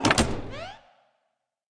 Sfx Marquee Dropdown Sound Effect
sfx-marquee-dropdown.mp3